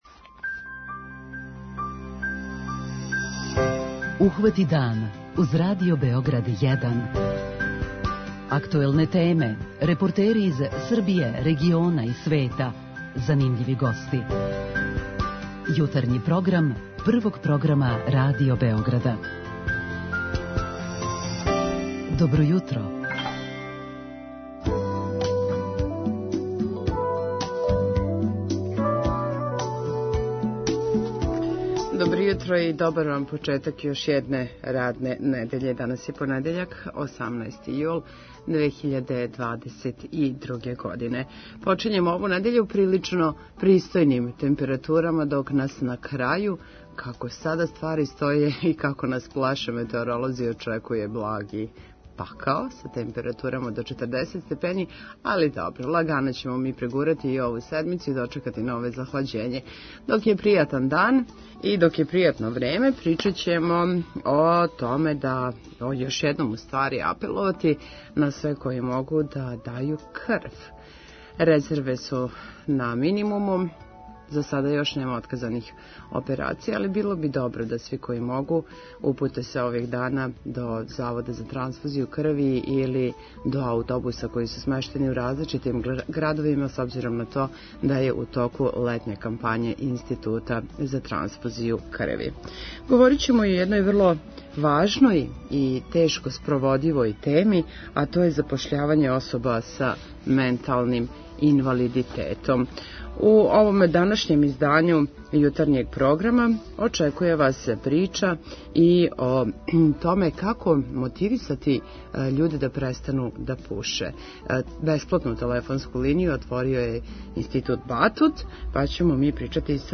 Са каквим проблемима се сусрећу ове особе говоре корисници и представници Иницијативе за права особа са менталним инвалидитетом.